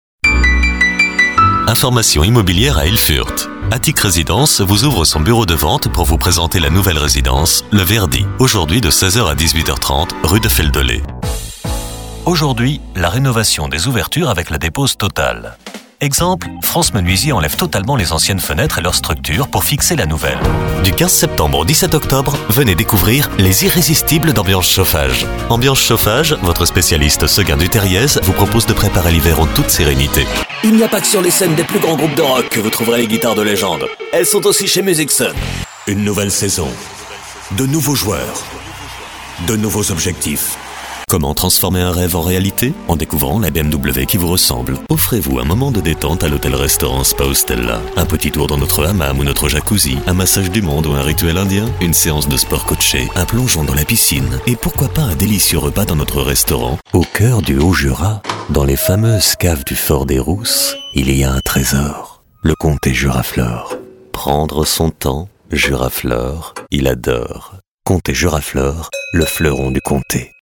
Sprechprobe: Werbung (Muttersprache):
A chameleon voice, warm and bass.